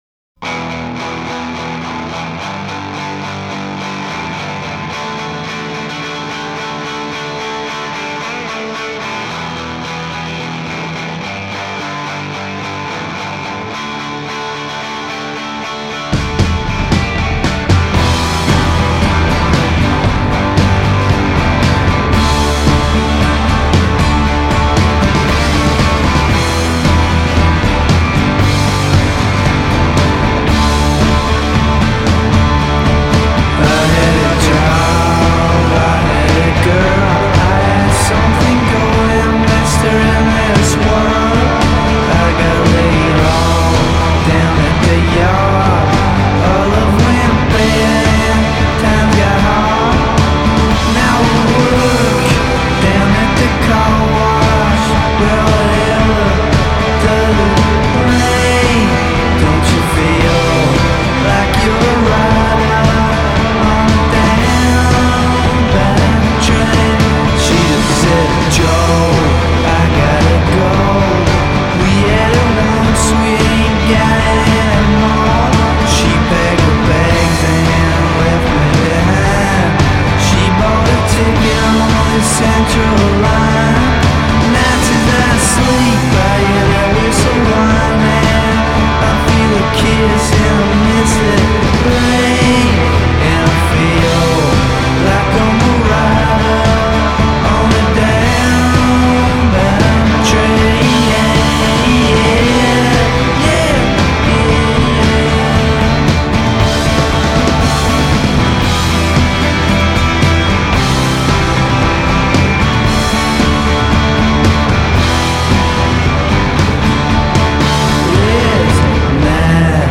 Fuzztacular lo-fi guitar man